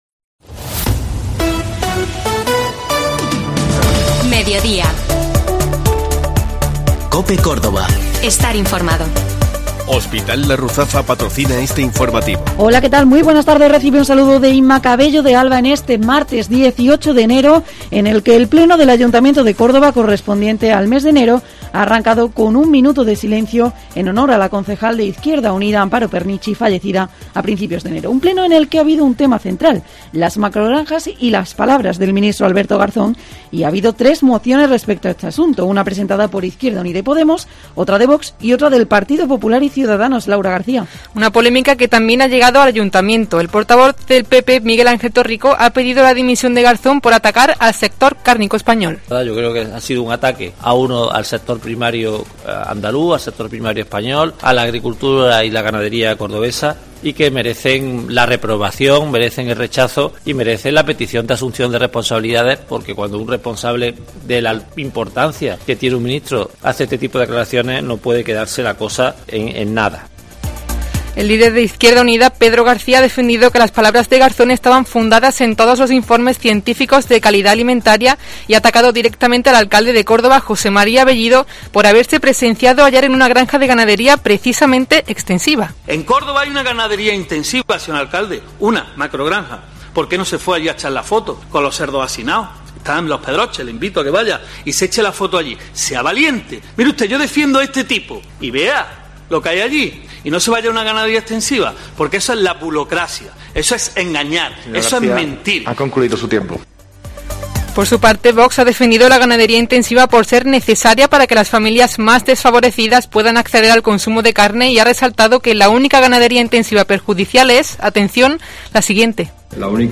LA ACTUALIDAD CADA DÍA